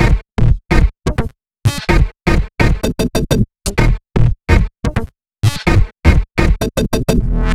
VEE Melody Kits 45 127 BPM Root D#.wav